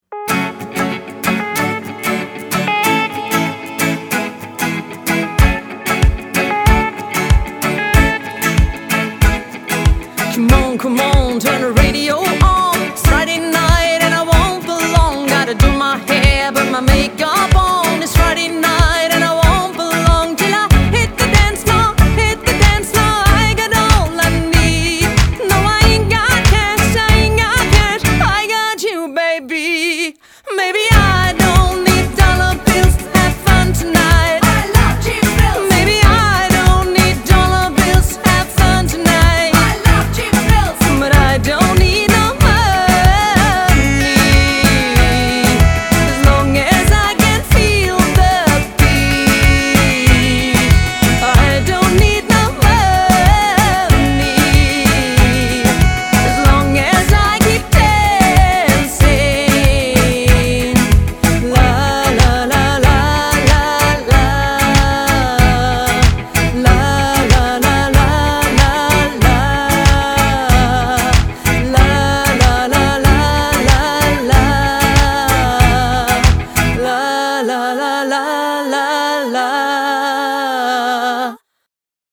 Lead-Gesang, Saxophon, Akkordeon
Bass
Drums